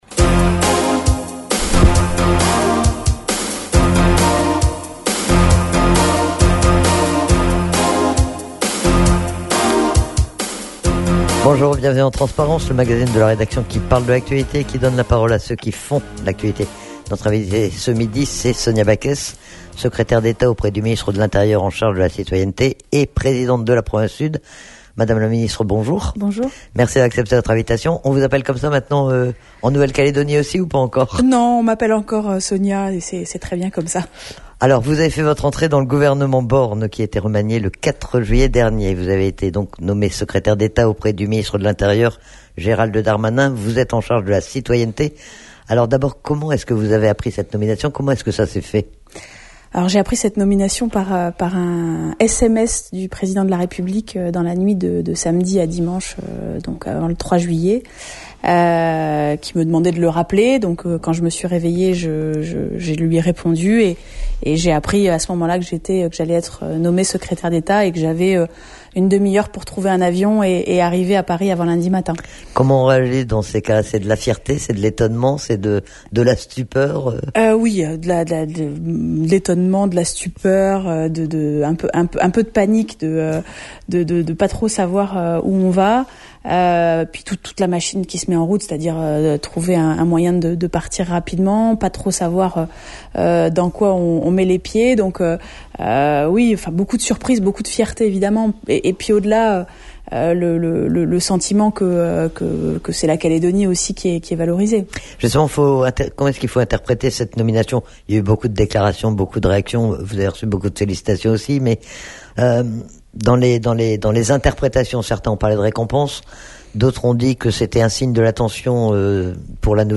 Menu La fréquence aux couleurs de la France En direct Accueil Podcasts TRANSPARENCE : VENDREDI 29/07/22 TRANSPARENCE : VENDREDI 29/07/22 28 juillet 2022 à 15:13 Écouter Télécharger Sonia Backès invitée de Transparence.